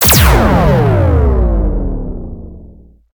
laserblast.ogg